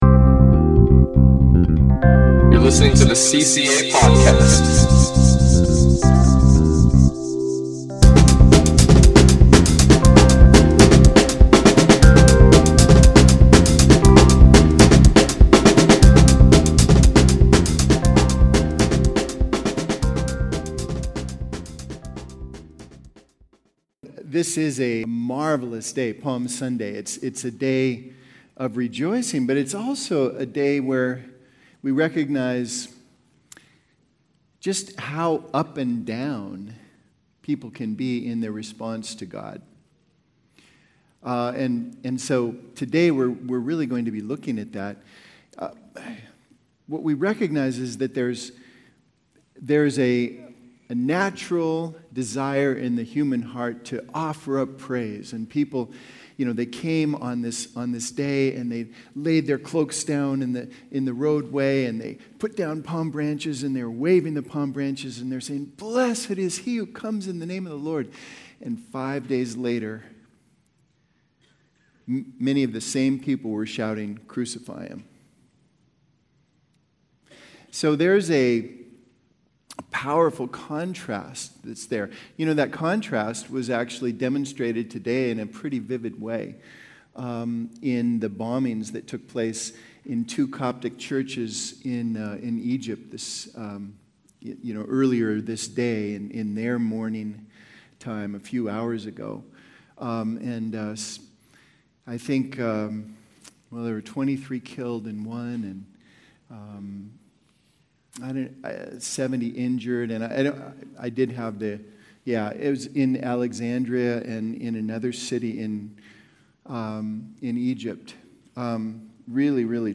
Please note - there were difficulties with the recording and the last 10 minutes did not record.